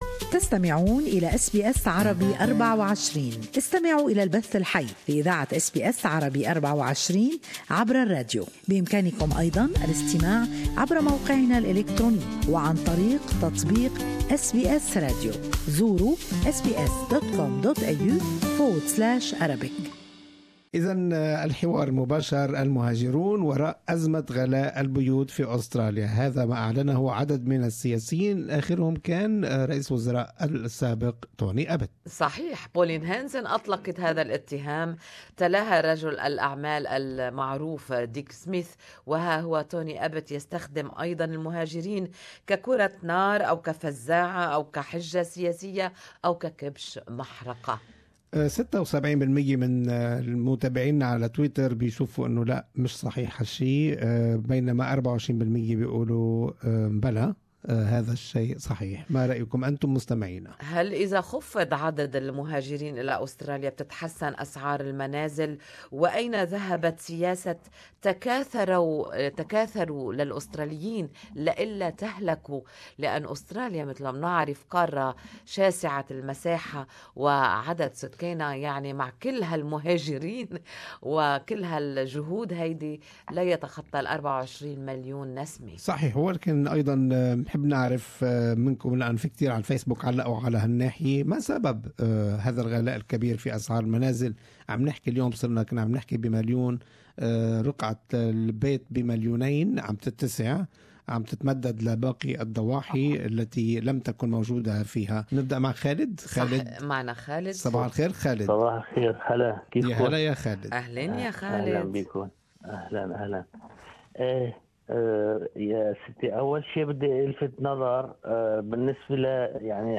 Good Morning Australia listeners share their opinions on this topic.